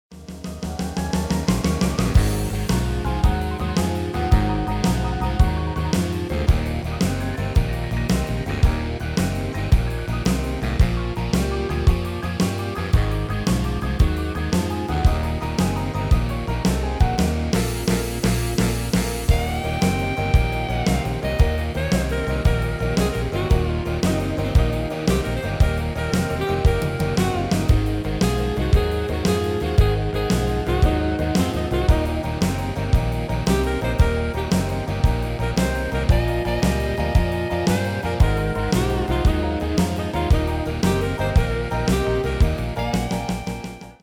GM-Only MIDI File Euro 8.50